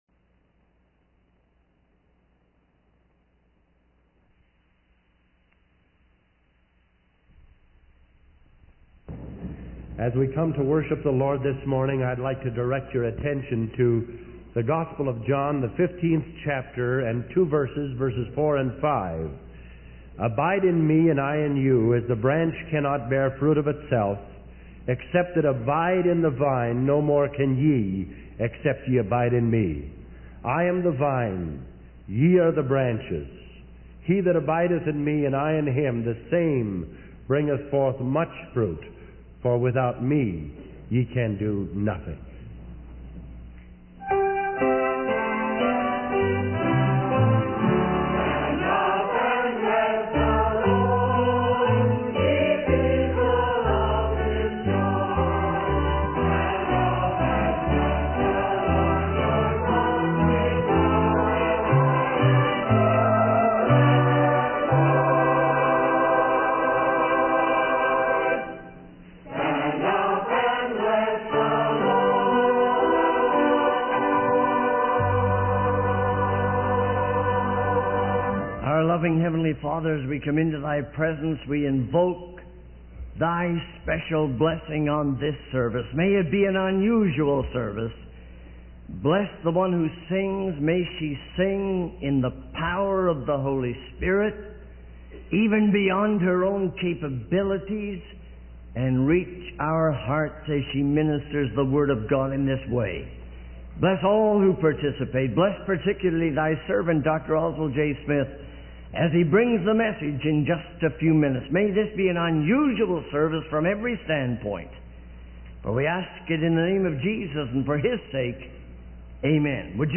In this sermon, the speaker reflects on the fleeting nature of human life and emphasizes the importance of recognizing the limited time we have on Earth.